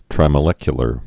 (trīmə-lĕkyə-lər)